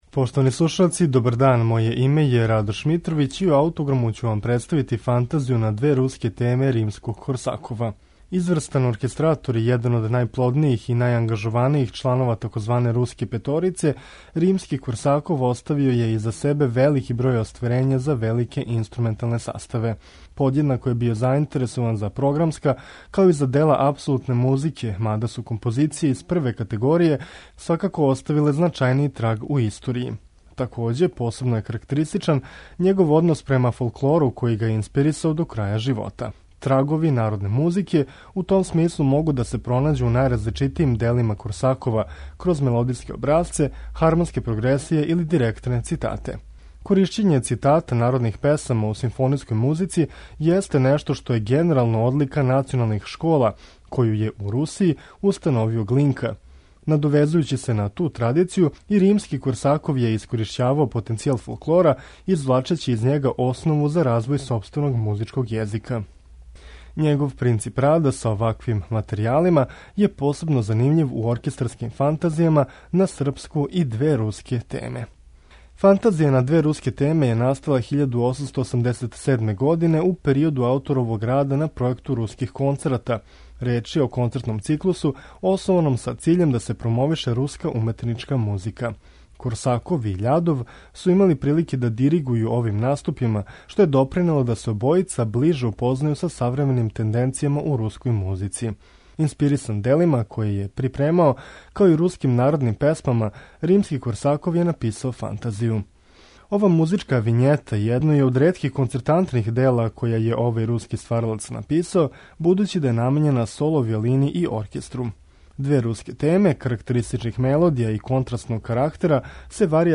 Ову другу слушаћемо у извођењу виолинисткиње Лидије Мордкович и Краљевског Шкотског националног оркестра, под управом Немеа Јервија.